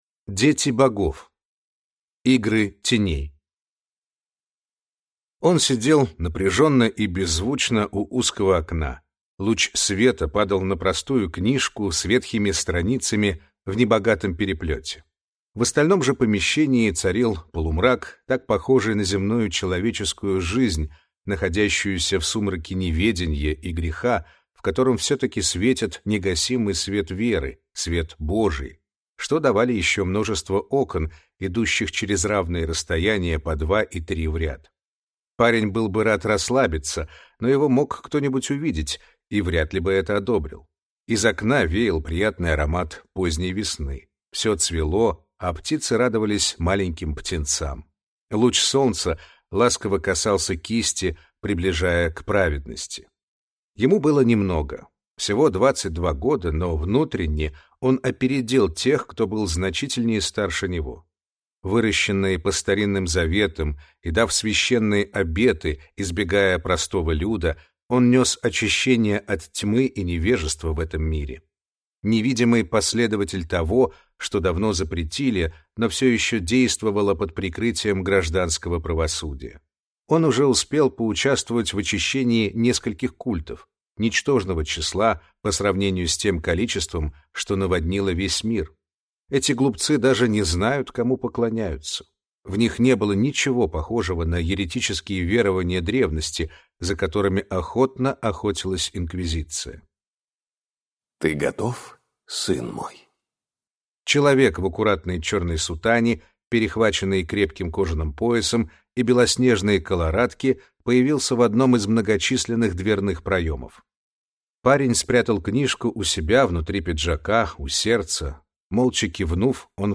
Аудиокнига Дети богов. Игры теней | Библиотека аудиокниг